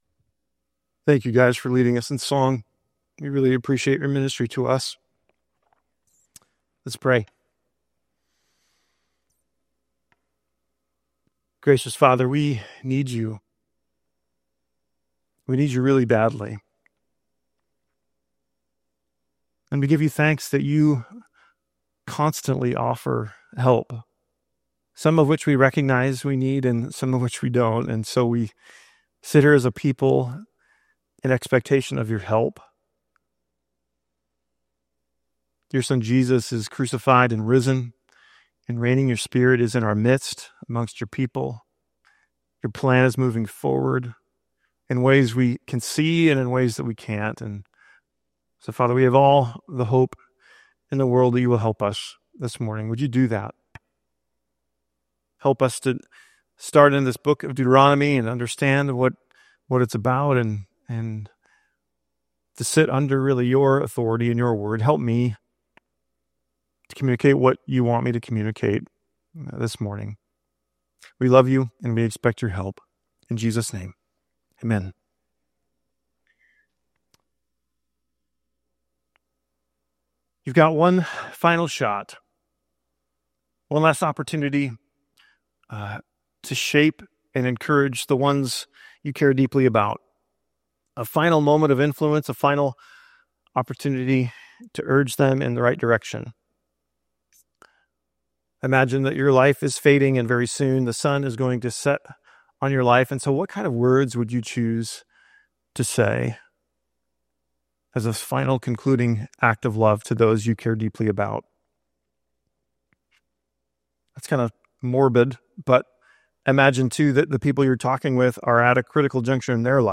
Audio feed of sermons, classes and events at Glenwood Community Church in Vancouver, Washington